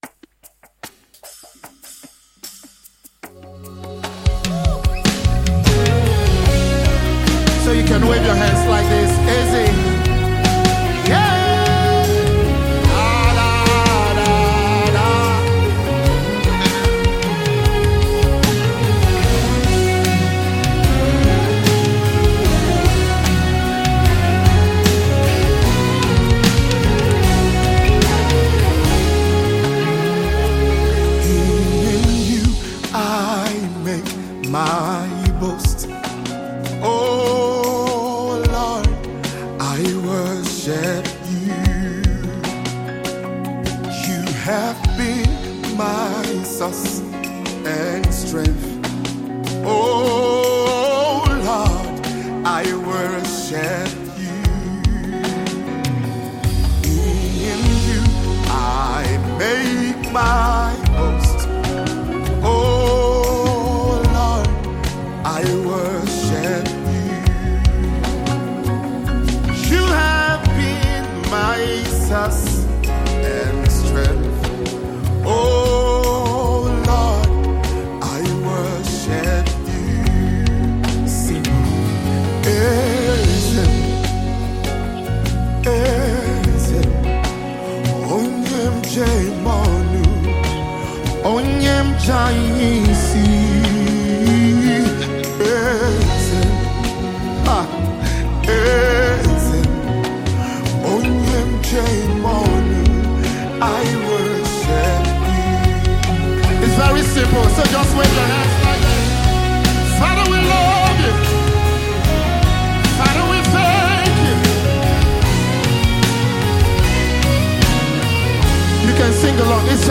Nigerian gospel artiste, songsmith, and worship leader
spirit-lifting song